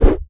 axe_swoosh2.wav